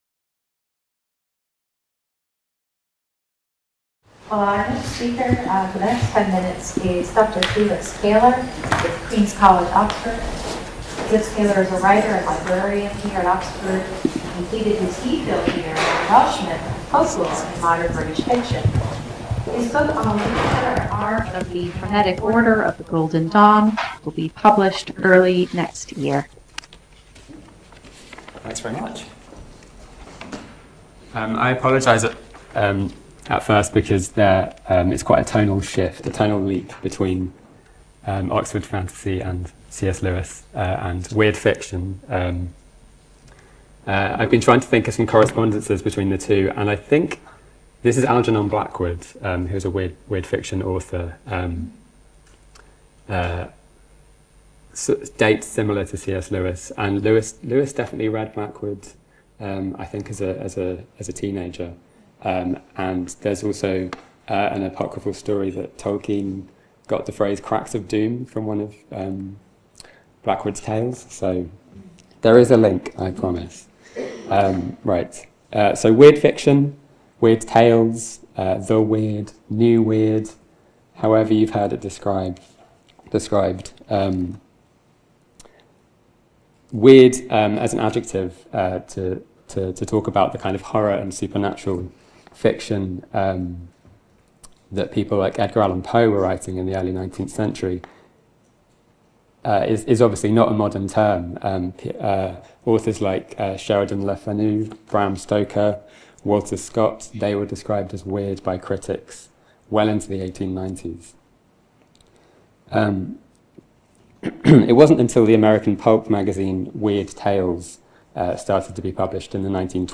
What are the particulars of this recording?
Short talk (10 minutes) - Part of the Bloomsbury-Oxford Summer School (23rd-25th September 2025) held at Exeter College.